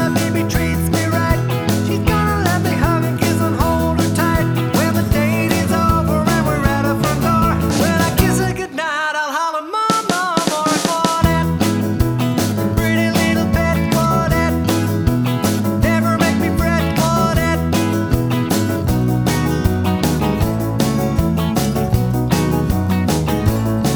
No Harmony Pop (1950s) 2:14 Buy £1.50